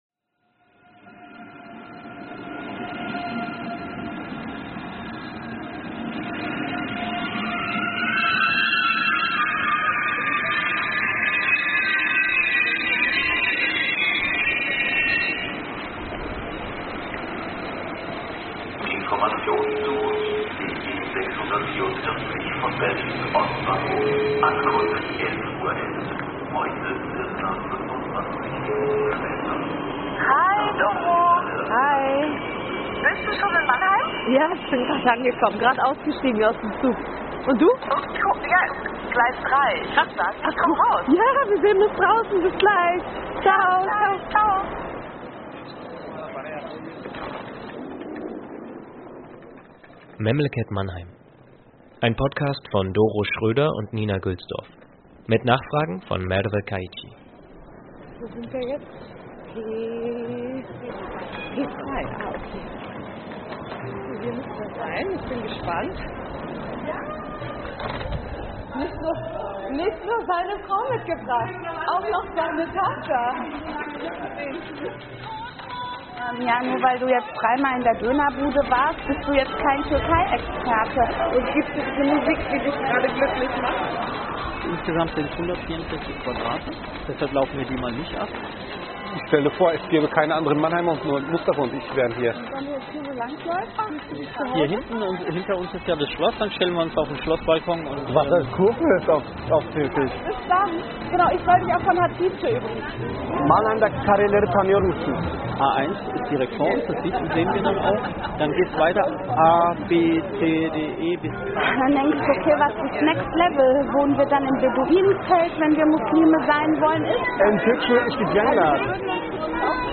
Mit den Interviewpartner*innen haben wir uns zu zwei Gruppengesprächen (Stammtischen) und zu 10 Einzelgesprächen getroffen.